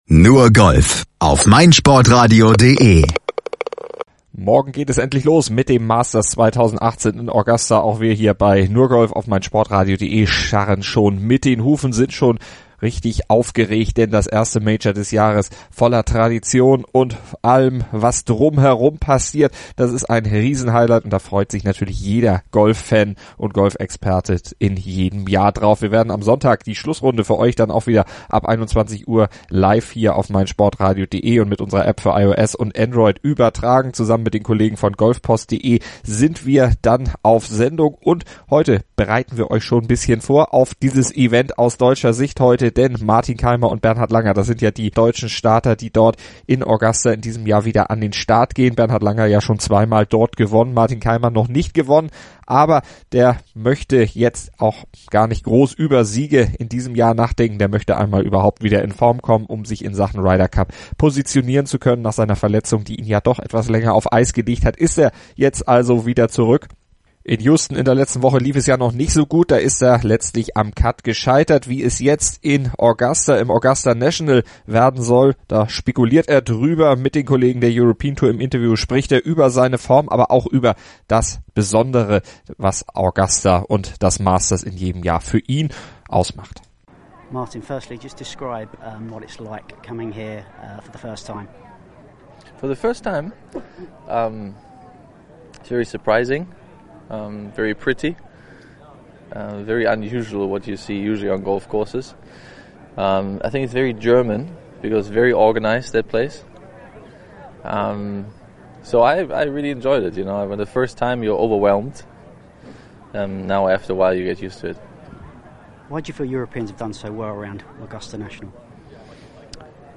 rechnet sich gute Chancen aus, wie er im Interview verrät.
in einem kleinen Vorbericht mit den Stimmen der beiden